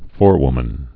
(fôrwmən)